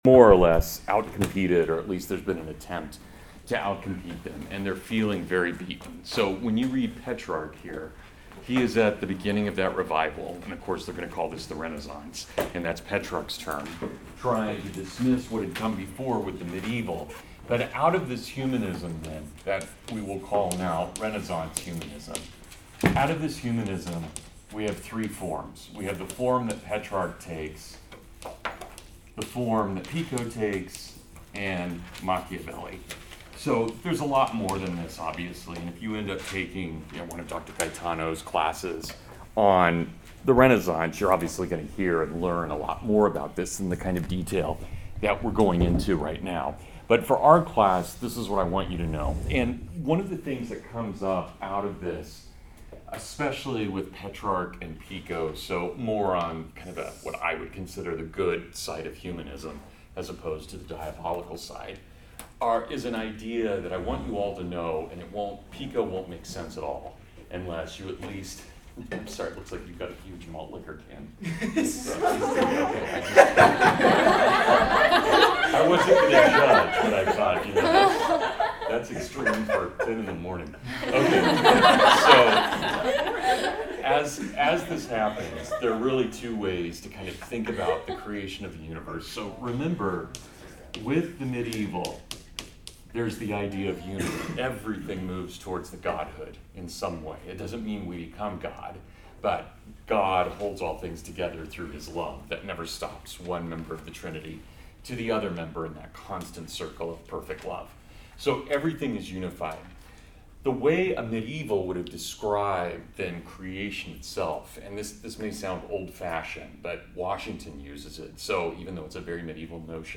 Petrarch (Full Lecture)